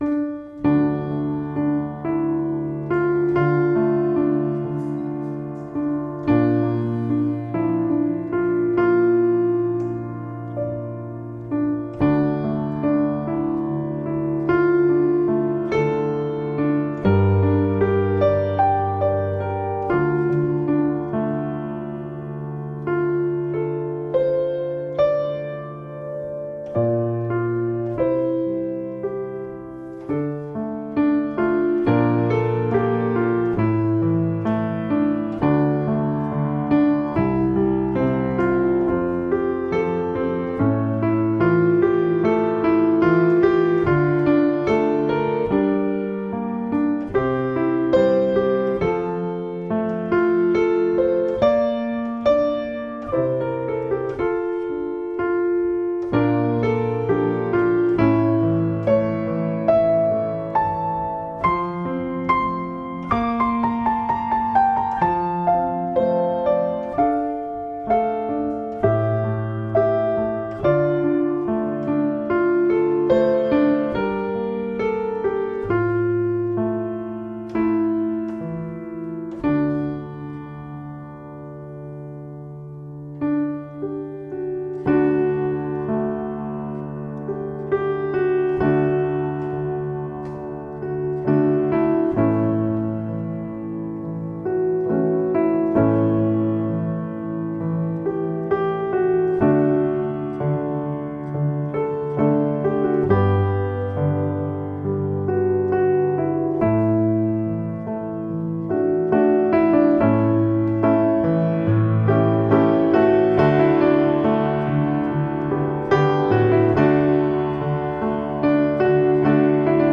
zwölfminütiges Adventsmedley
Pianist